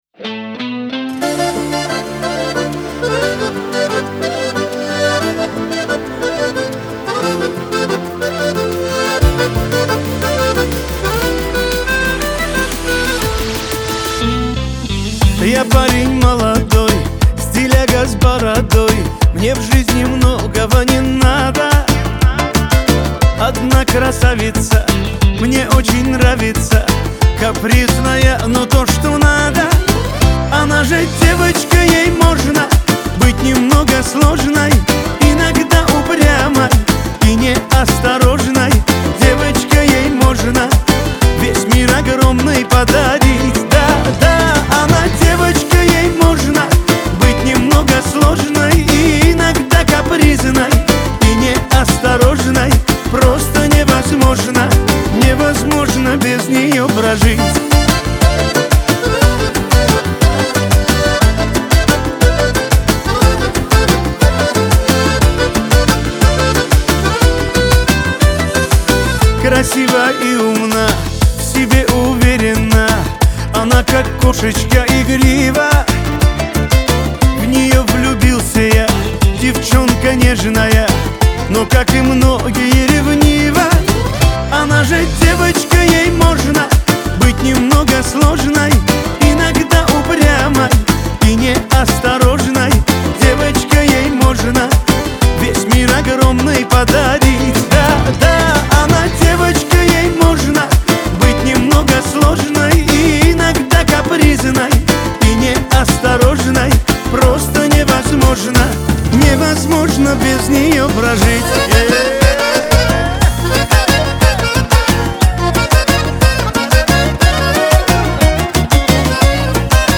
диско
Кавказ поп